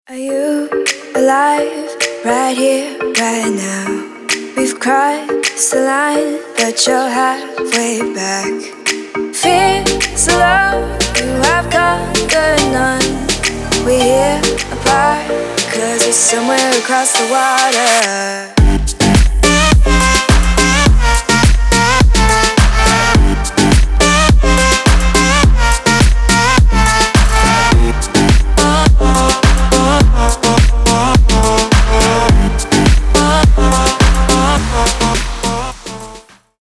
• Качество: 224, Stereo
поп
женский вокал
dance
Electronic
EDM